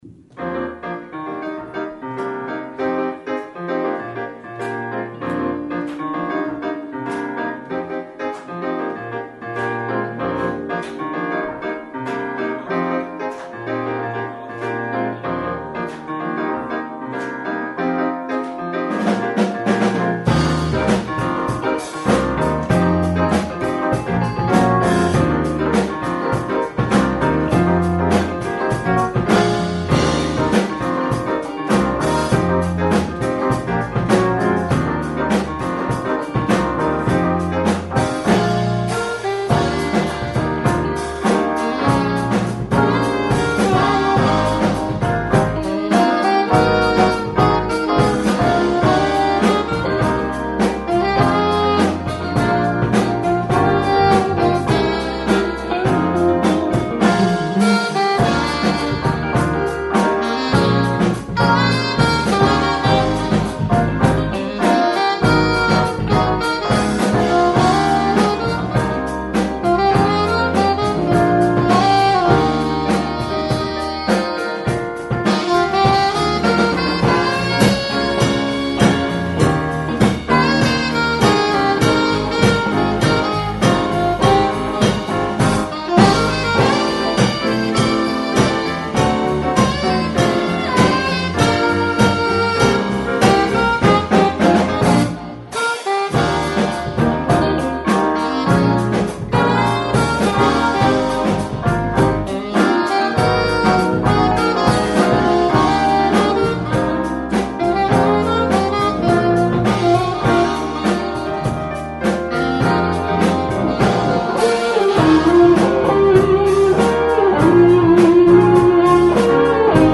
연주 너무 좋아요!!!
어찌저찌 하다보니 라이브버젼과 원곡버젼을 짬뽕해서 했네요^^